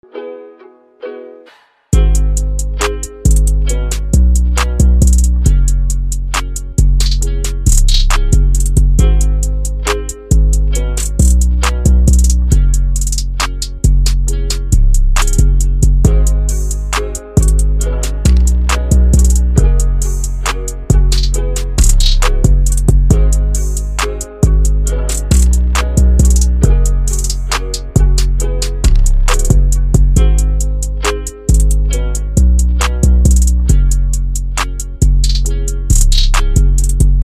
Categories: Instrumental